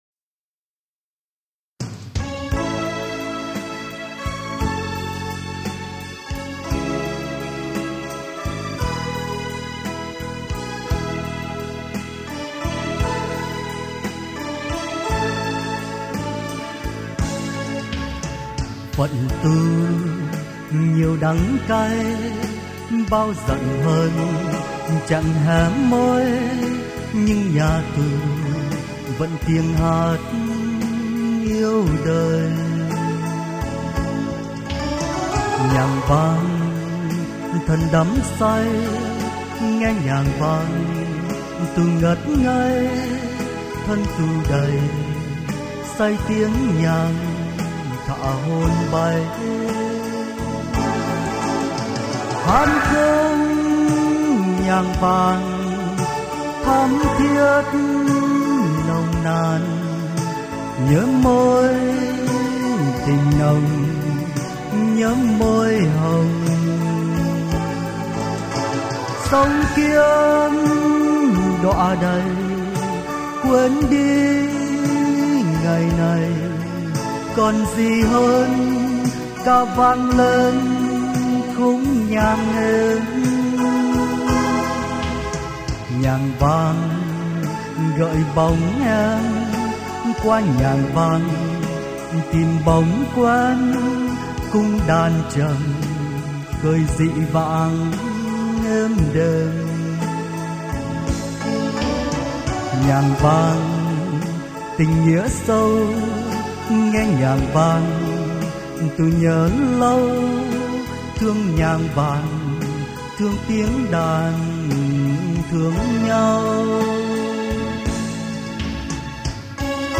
Tù Khúc